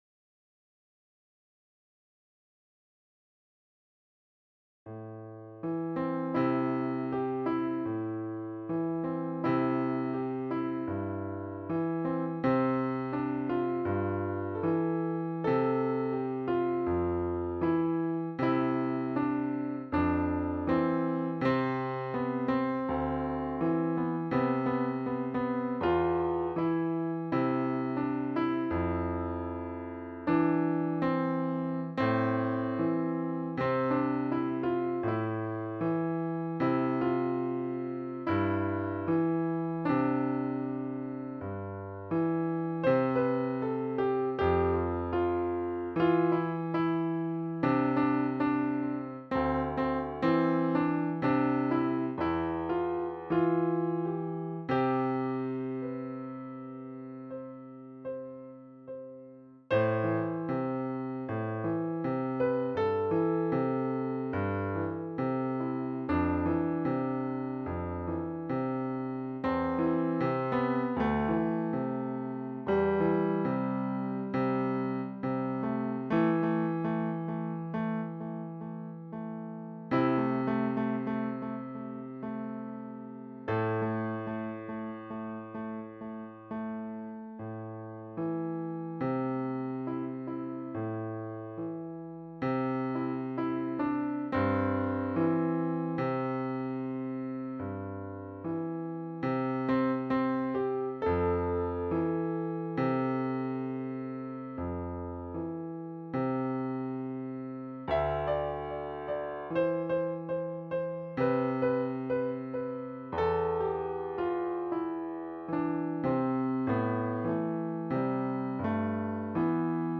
Posted in Piano Pieces Comments Off on